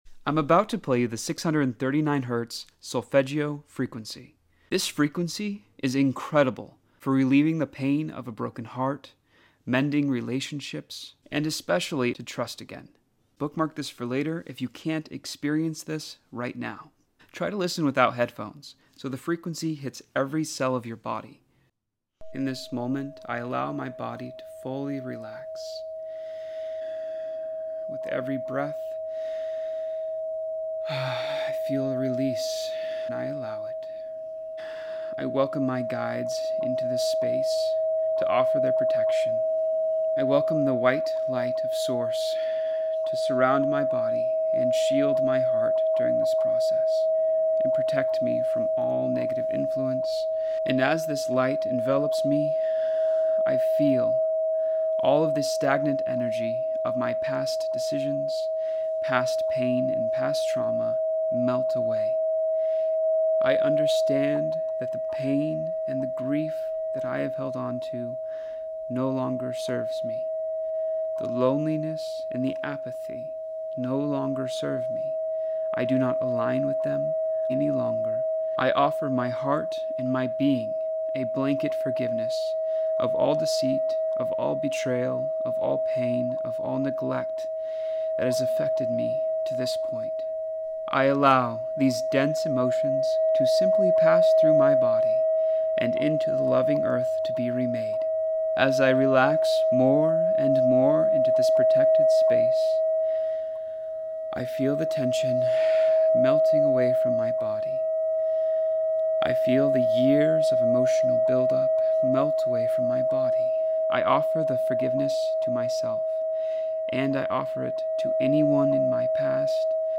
Heal Your Heart ❤ Finding Love w/ 639 Hz Solfeggio Frequency 🙌 A mindfulness meditation activity 🙏 with powerful affirmations to manifest your dream relationship.